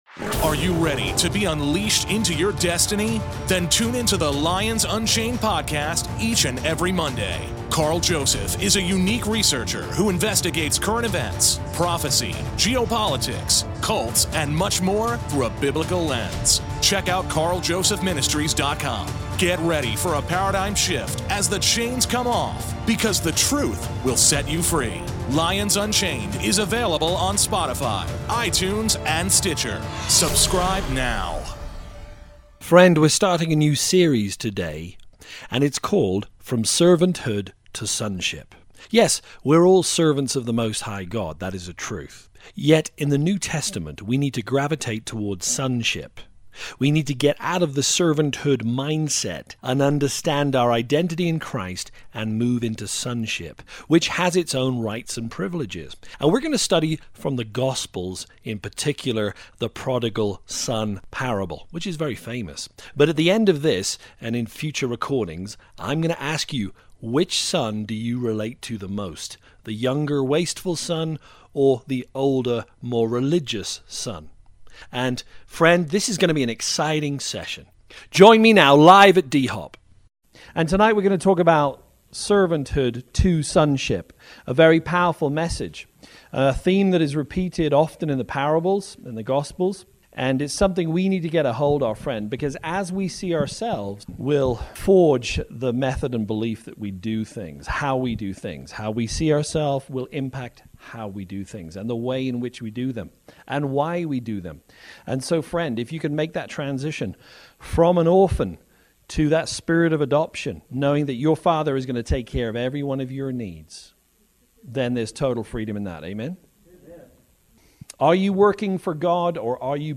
Part 1 (LIVE)